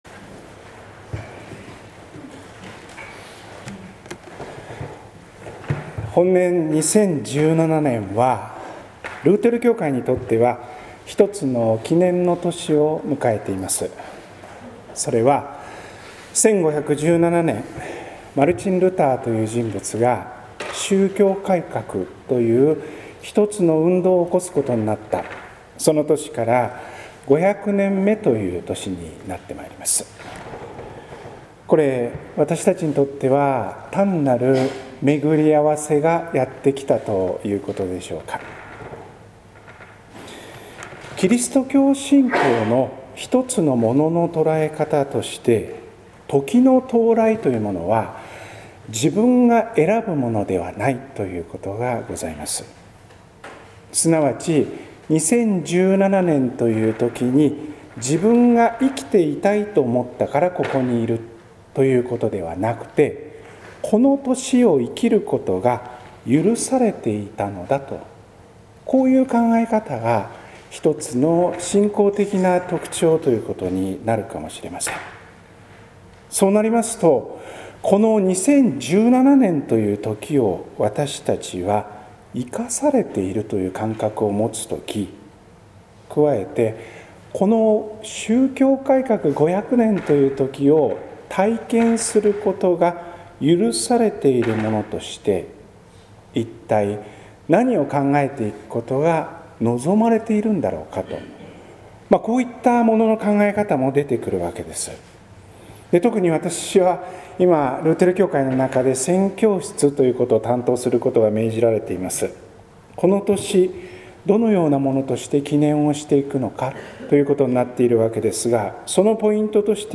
説教「良質な誇りの光、いのちに灯れ」（音声版） | 日本福音ルーテル市ヶ谷教会